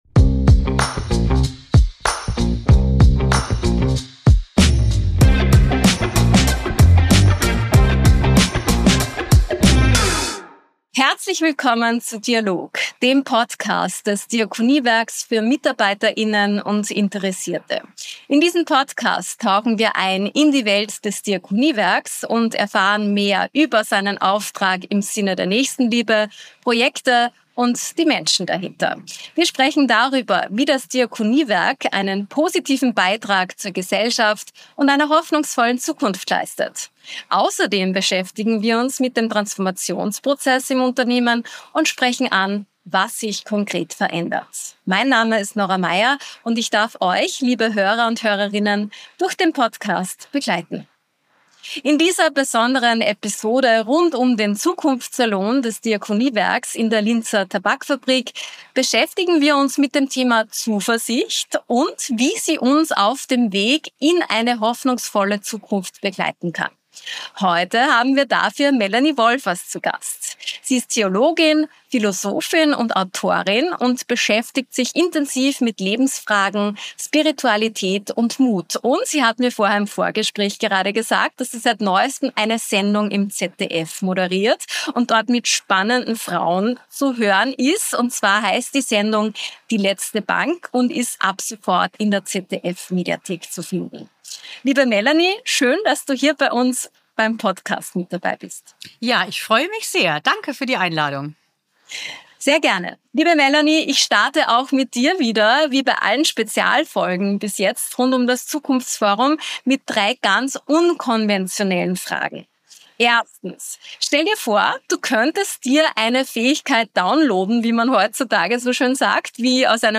Spezialfolge zum Zukunftssalon: Zuversicht in Zeiten des Wandels – im Gespräch